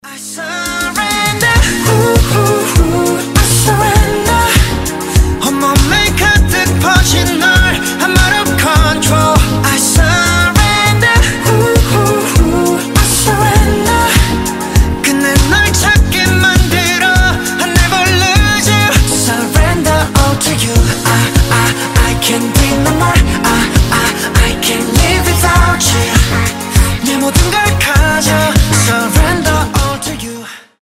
K-Pop
Приятный корейский поп